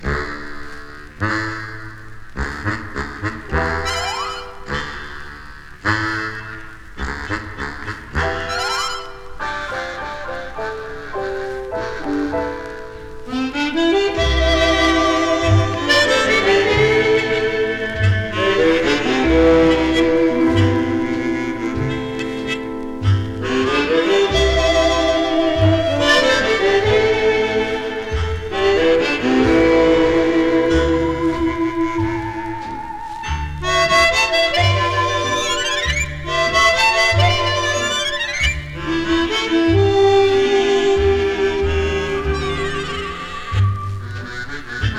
ハーモニカ奏者
針をのせると、ふんわりと気持ちほどける夢見心地サウンドが実に気持ちよいのですが、実は只者じゃないレコード。
Jazz, Easy Listening, Strange　USA　12inchレコード　33rpm　Stereo